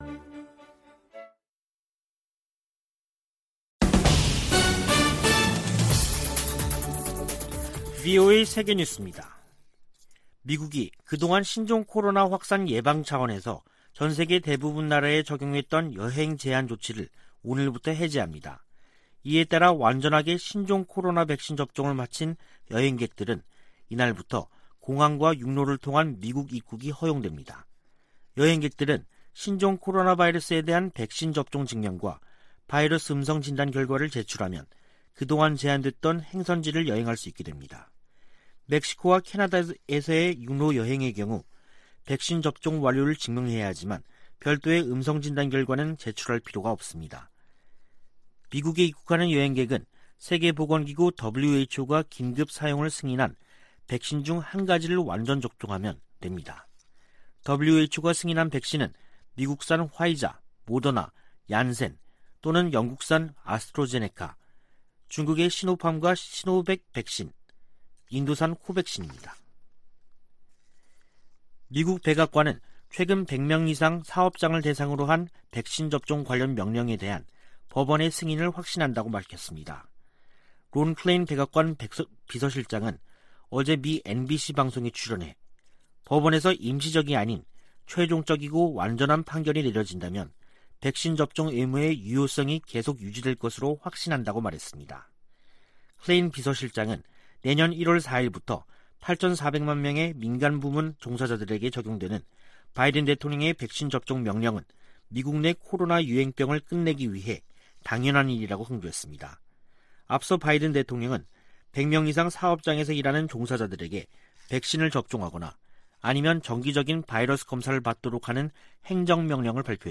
VOA 한국어 간판 뉴스 프로그램 '뉴스 투데이', 2021년 11월 8일 2부 방송입니다. 북한의 광범위한 인권 침해를 규탄하고 책임 추궁과 처벌을 촉구하는 올해 유엔 결의안 초안이 확인됐습니다. 미 국제개발처(USAID)가 북한 내 인권과 인도적 상황에 깊은 우려를 나타냈습니다. 중국과 북한 당국이 협력해 북한 국적 기독교인들을 색출하고 있다고 미국의 기독교 단체인 ‘가정연구협회’가 밝혔습니다.